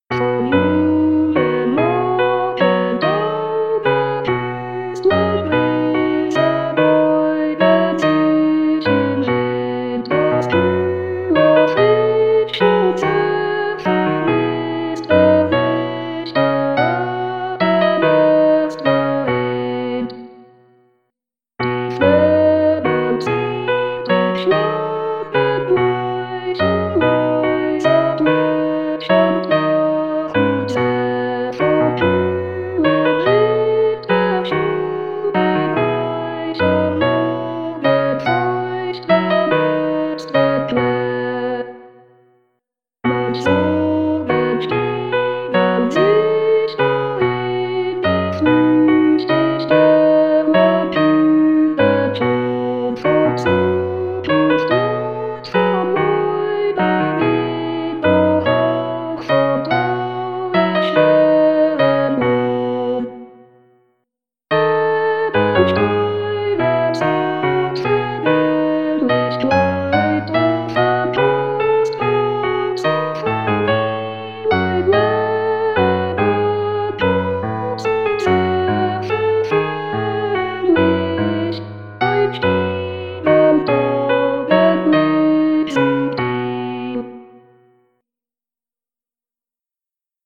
mp3-Aufnahme: Wiedergabe mit Gesang